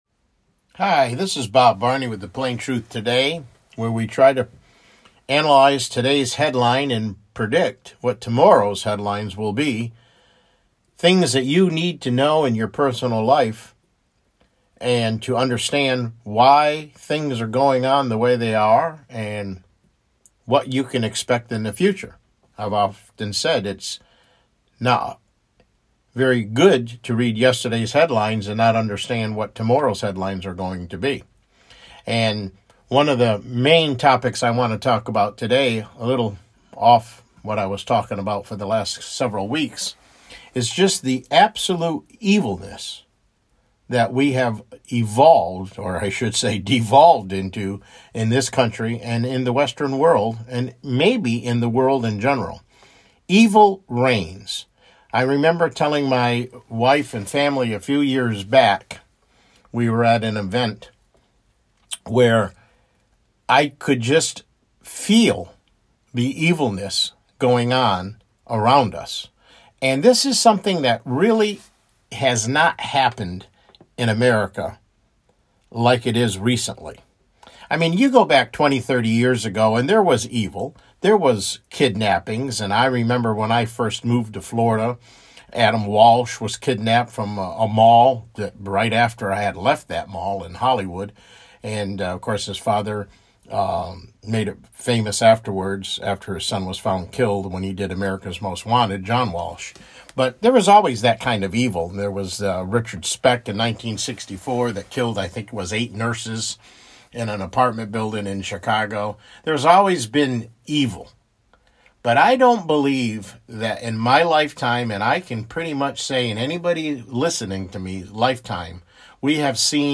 MIDDAY BROADCAST